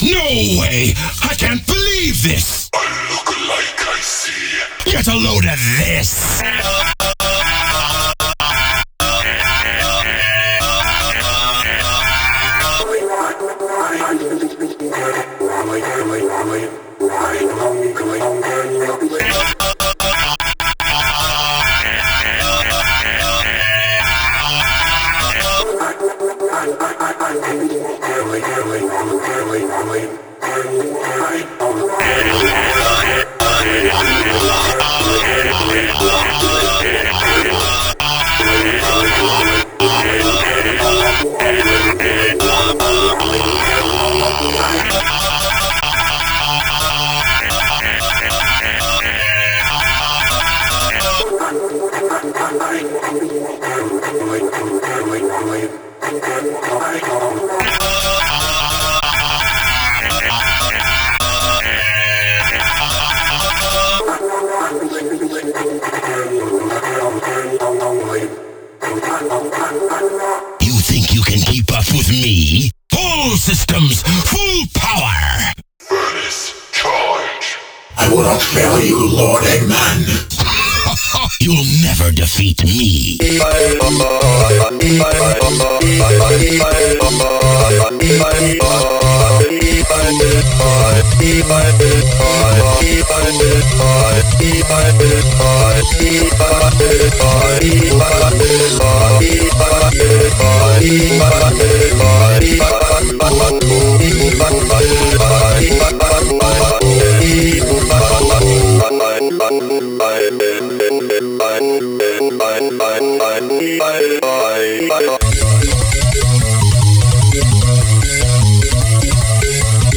Heres the Inst/Vocals (BPM is 150, no bpm changes!)